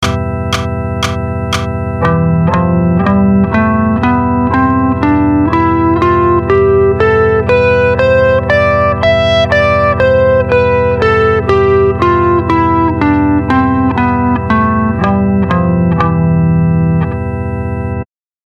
Phrygian Mode
Jumping back to the 3rd scale degree takes us to the dark sounding Phrygian mode. Sticking with the C major scale the 3rd scale degree is E so E Phrygian uses the notes of C major starting on E. This mode sounds dark due to the minor second interval between the 1st and 2nd notes of the scale which in E Phrygian is the E and F notes.
e_phrygian.mp3